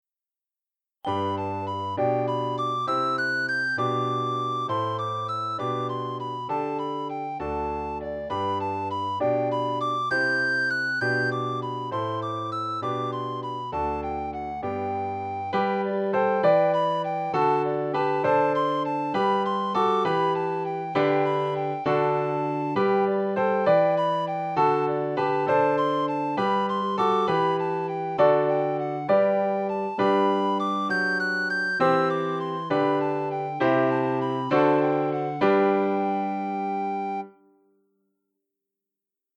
für Sopranblockflöte (Violine, Flöte) und Klavier